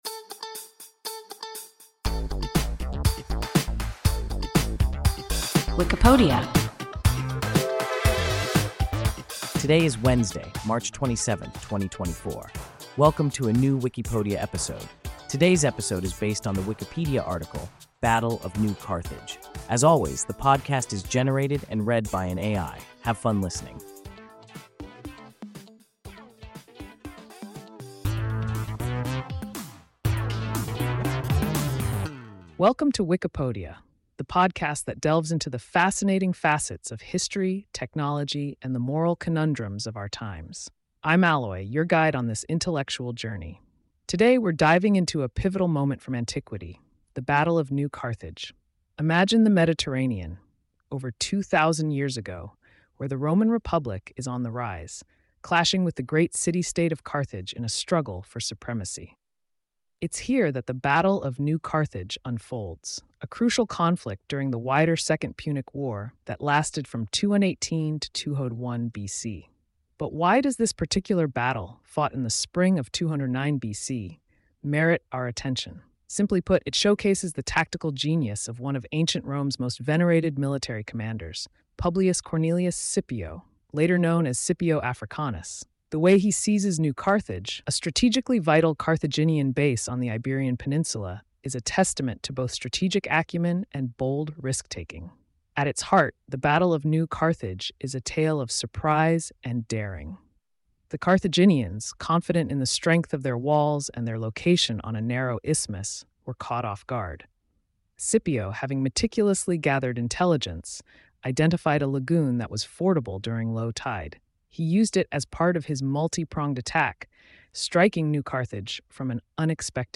Battle of New Carthage – WIKIPODIA – ein KI Podcast